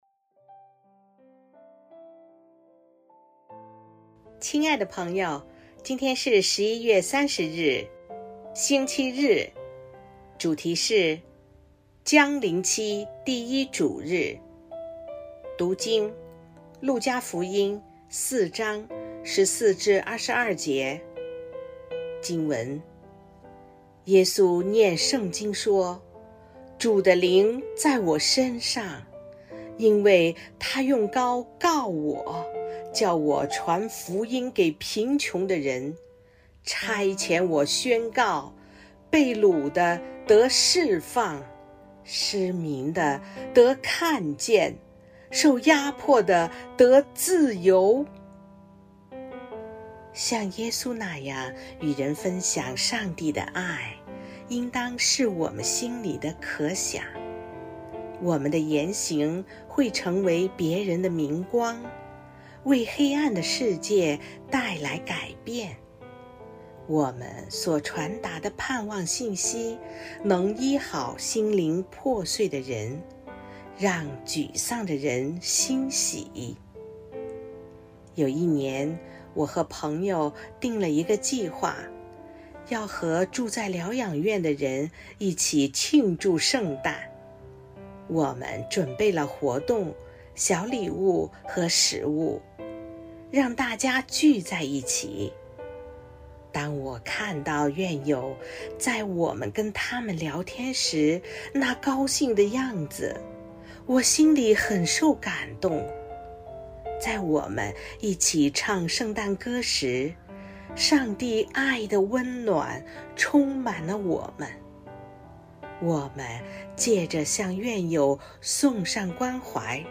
錄音員